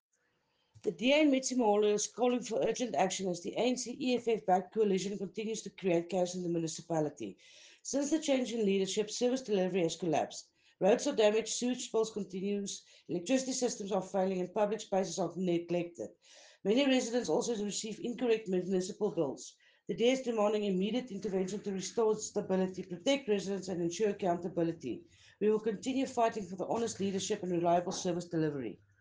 Afrikaans soundbites by Cllr Linda Day and